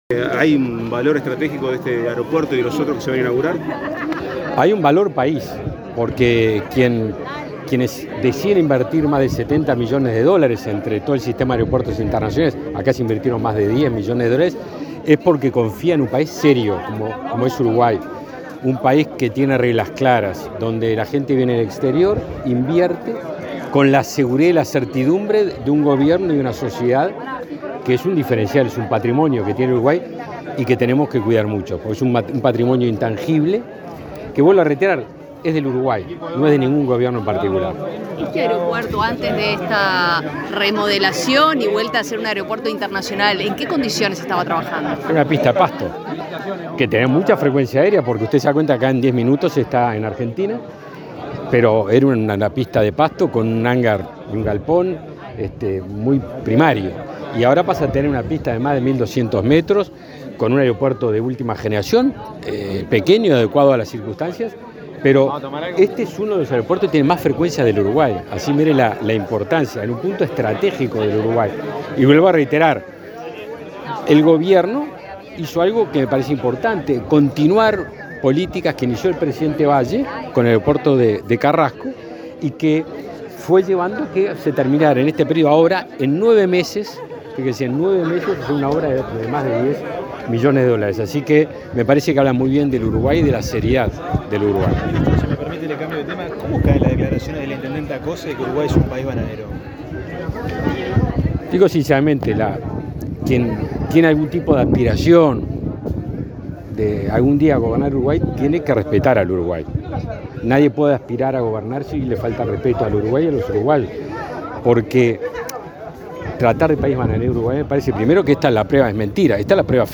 Declaraciones a la prensa del ministro de Defensa Nacional, Javier García
Tras participar en la inauguración del aeropuerto internacional de Carmelo, este 19 de diciembre, el ministro de Defensa Nacional, Javier García,